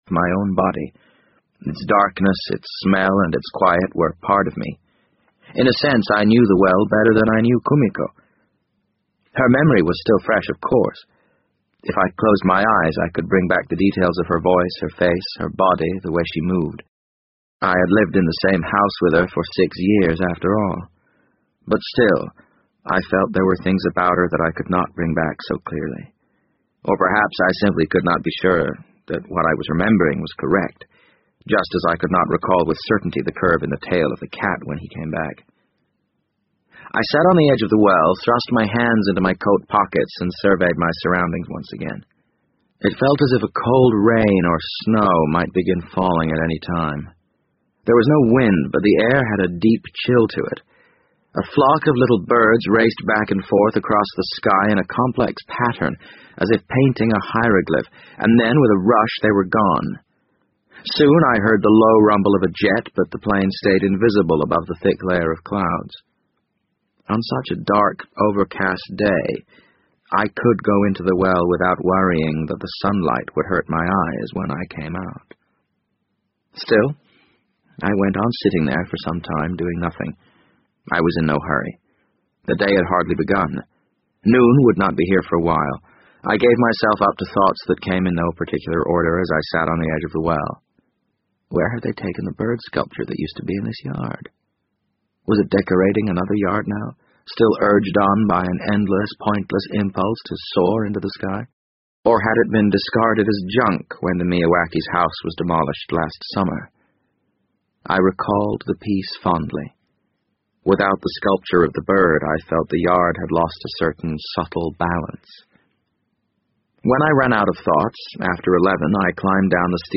BBC英文广播剧在线听 The Wind Up Bird 014 - 9 听力文件下载—在线英语听力室